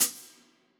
Boom-Bap Hat CL 53.wav